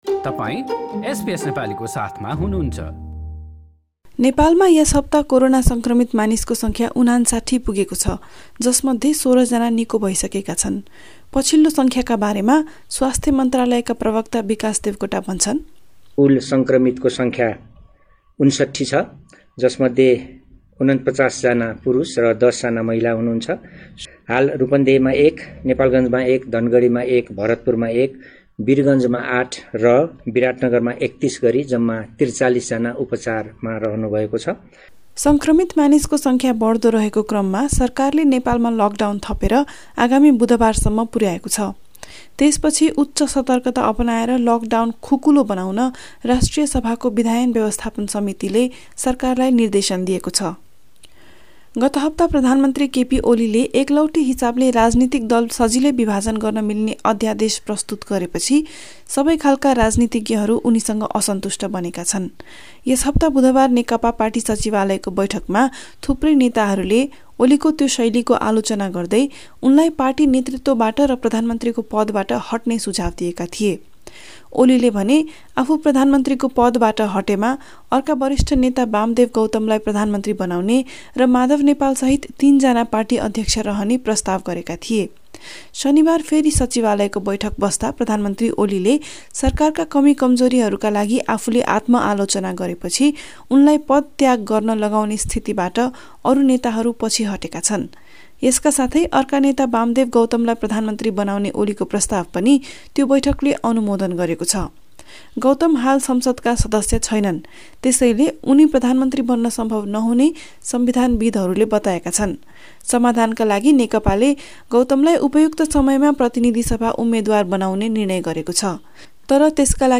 अडियो रिपोर्ट सुन्न माथि रहेको मिडिया प्लेयरमा क्लिक गर्नुहोस् कोरोना नेपालमा यस हप्ता कोरोना संक्रमित मानिसको संख्या ५९ पुगेको छ, जसमध्ये १६ जना निको भइसकेका छन्।